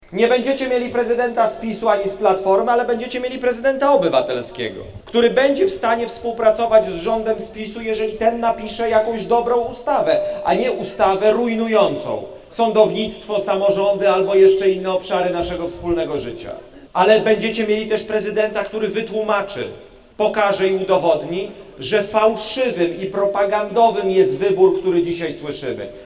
Na spotkanie na zielonogórski deptak przyszło ponad 200 osób.
W swoim wystąpieniu podkreślał, że jest kandydatem obywatelskim i krytykował partie polityczne: